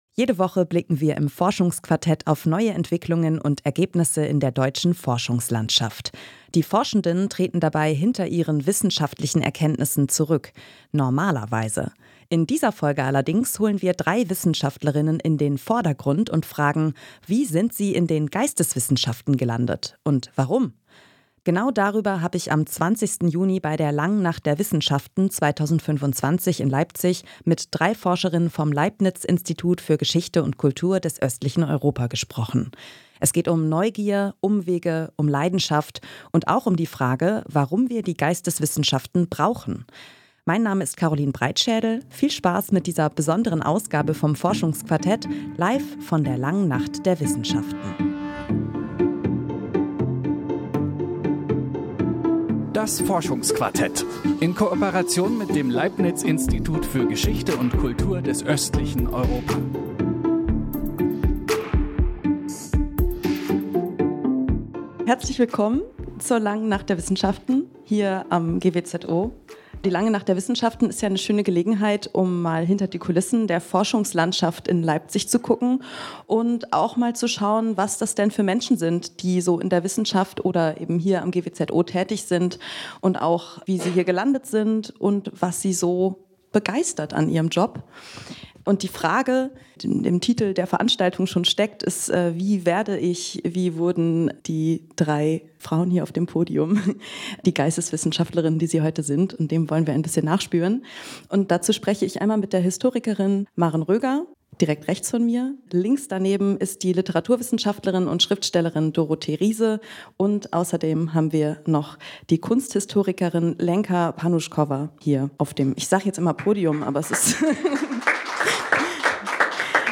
Forschungsquartett | Live von der Langen Nacht der Wissenschaften 2025
Das diskutieren drei Forscherinnen vom Leibniz-Institut für Geschichte und Kultur des östlichen Europa.